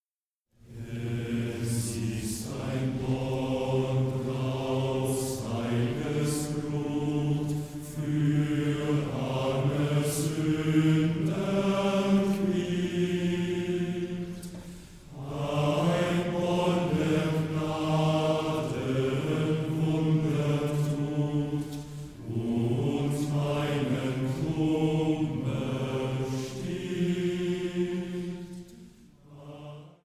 • kurzweilige Zusammenstellung verschiedener Live-Aufnahmen
Männerchor, Streicher